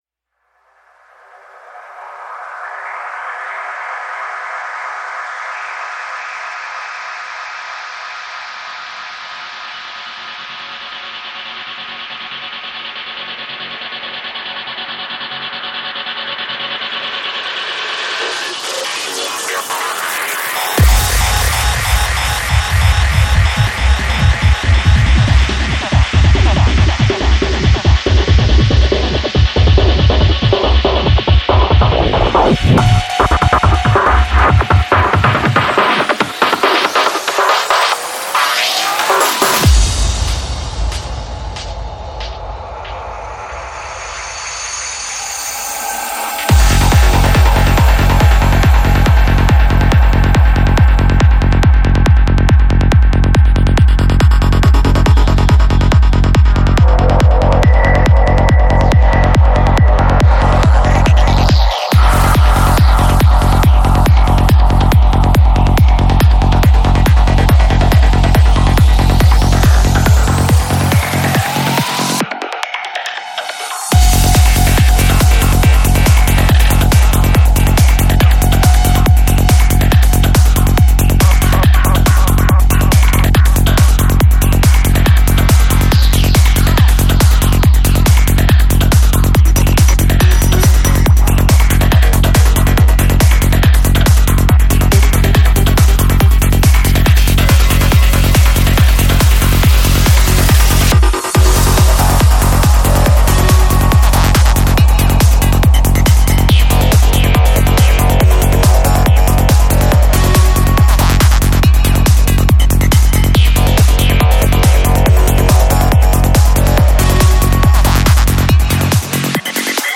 Жанр: Dance
Psy-Trance